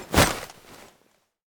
axe_swing.ogg